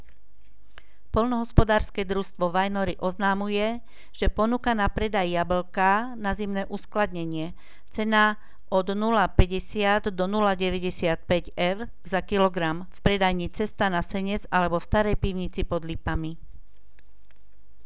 Hlásenie miestneho rozhlasu 8.10.2015